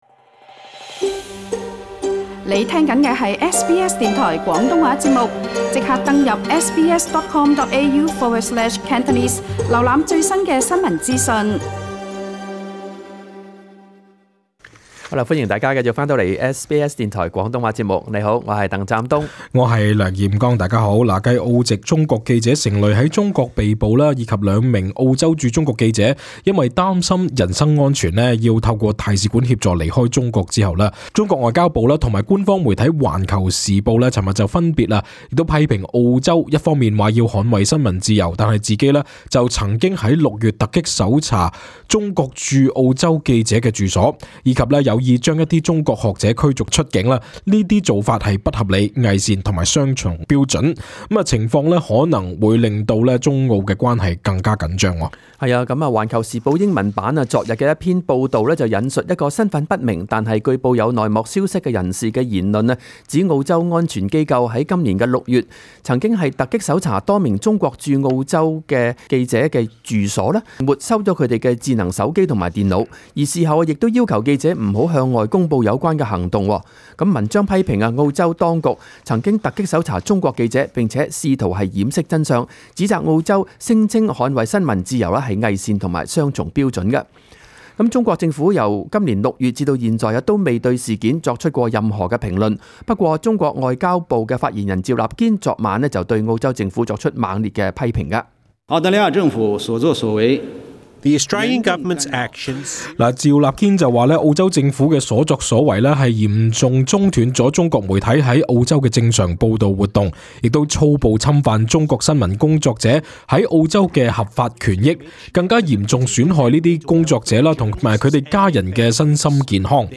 更多詳情請聽本台錄音報導。